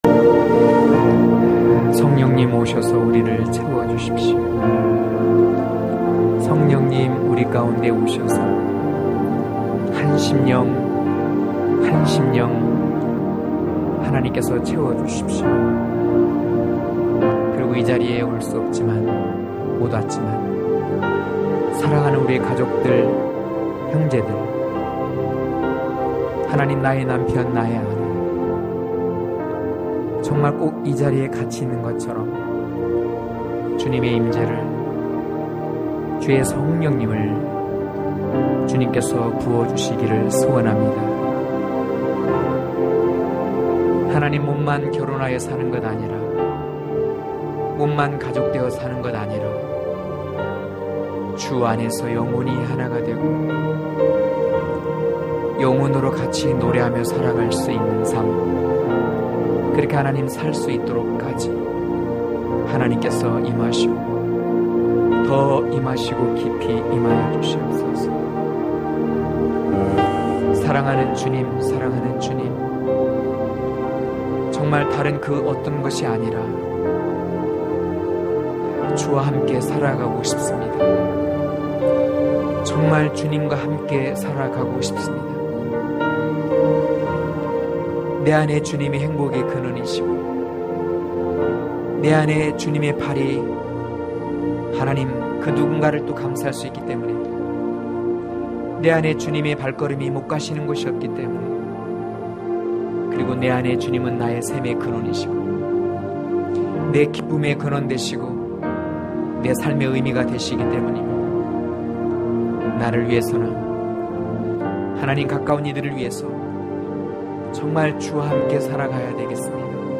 강해설교 - 10.내 어머니의 방으로..(아3장4-11절)